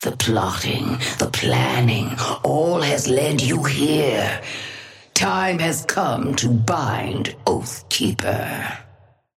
Sapphire Flame voice line - The plotting, the planning, all has led you here.
Patron_female_ally_ghost_oathkeeper_5b_start_03_alt_01.mp3